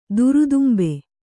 ♪ durudumbe